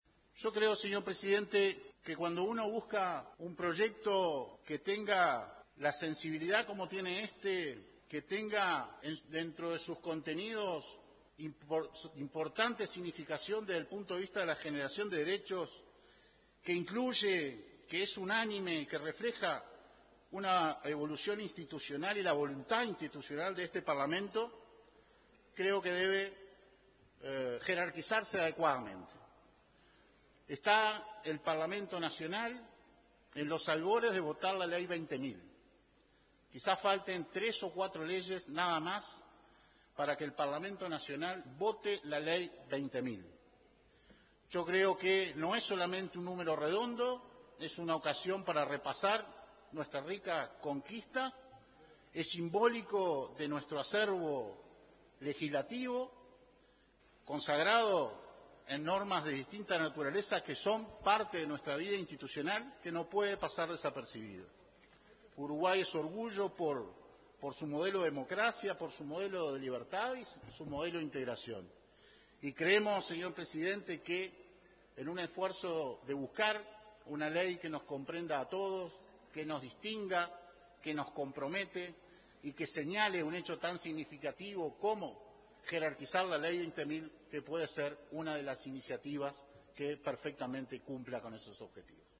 El diputado nacionalista Álvaro Viviano dijo en sesión que la ley «Federica» debe jerarquizarse adecuadamente.